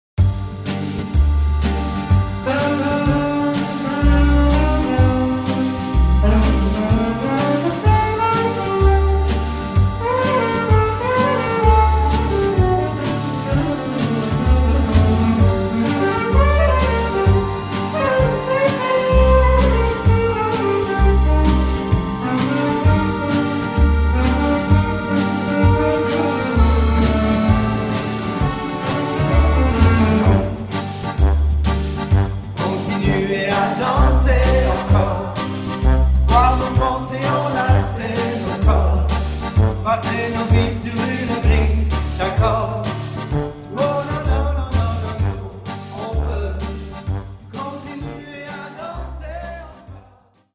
version live avec choeurs